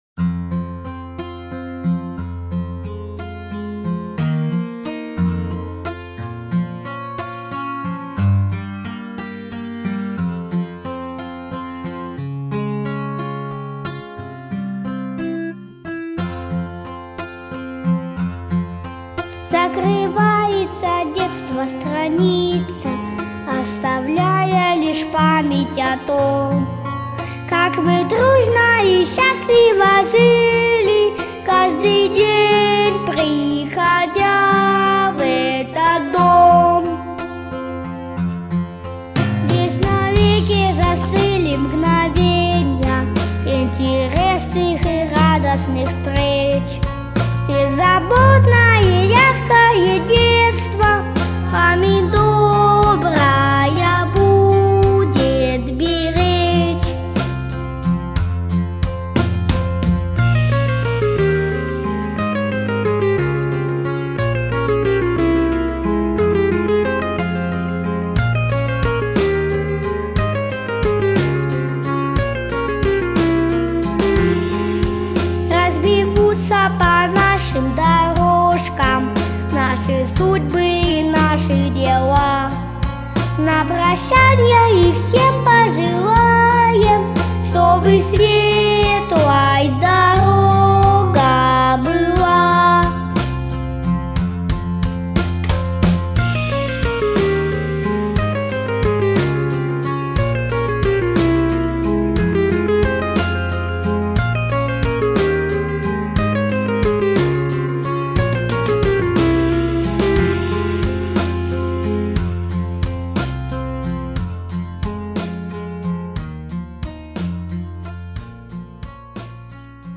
🎶 Детские песни / Детский сад / Выпускной в детском саду